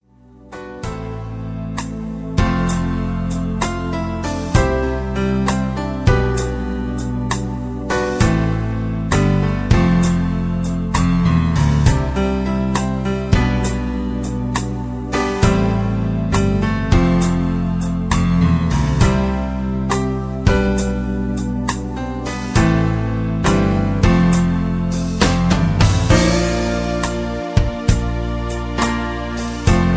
Karaoke MP3 Backing Tracks
Just Plain & Simply "GREAT MUSIC" (No Lyrics).